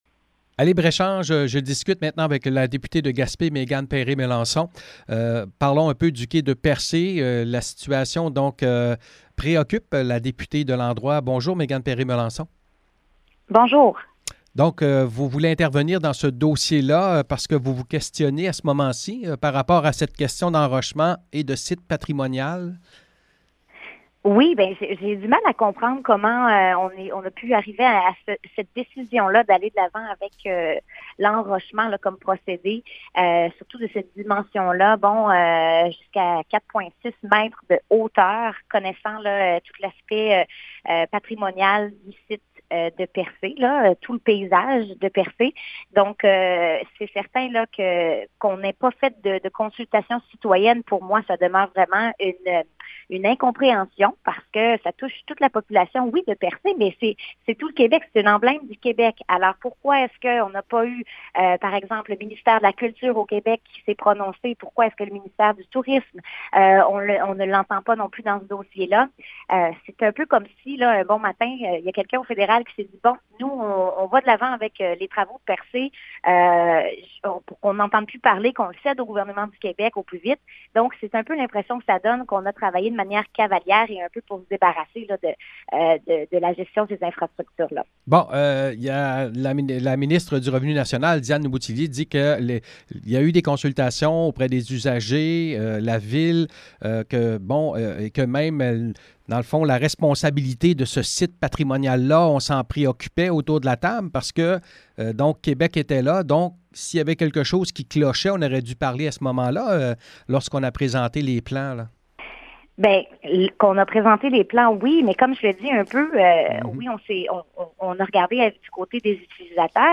Entrevue avec Méganne Perry Mélançon: